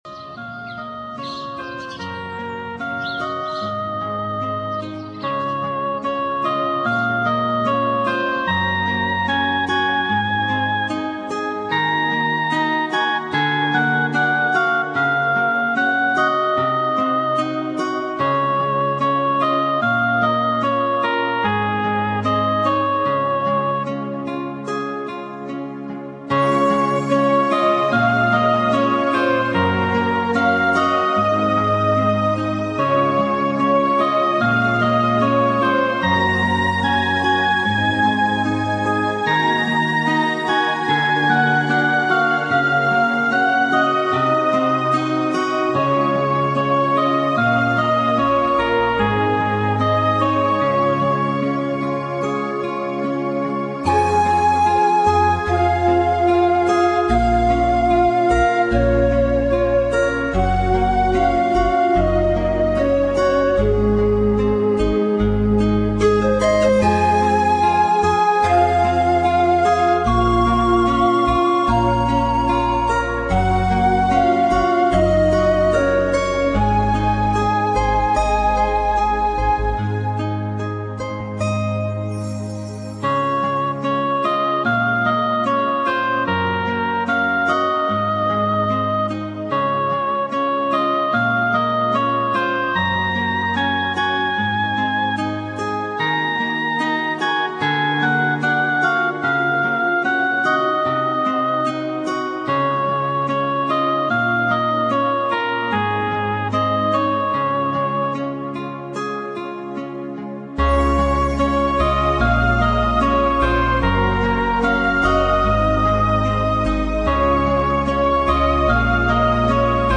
是纯美音乐与大自然音响的完美融合。